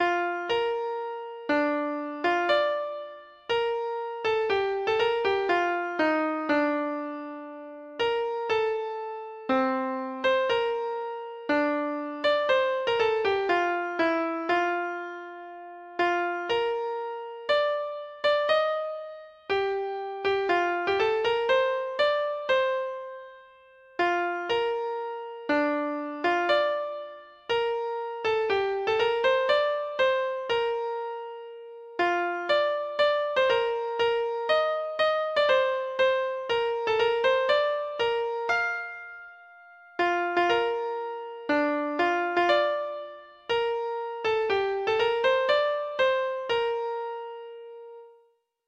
Free Sheet music for Treble Clef Instrument